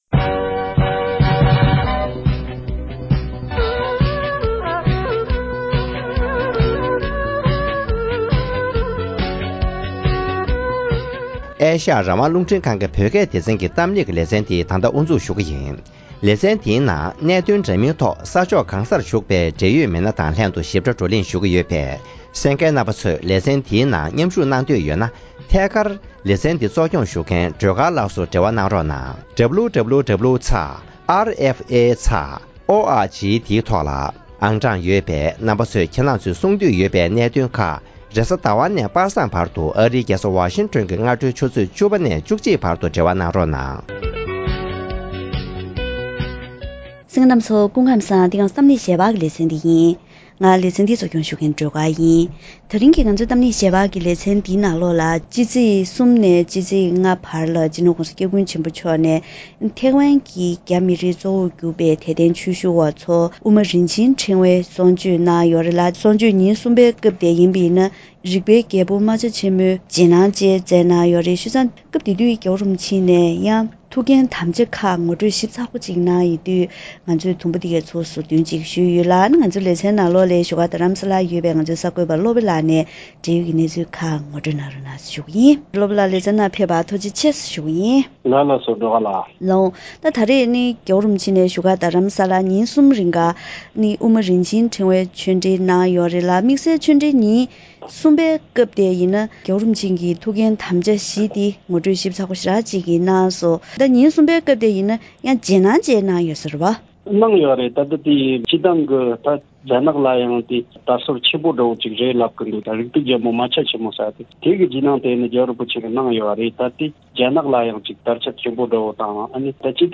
གཏམ་གླེང་ཞལ་པར་ལེ་ཚན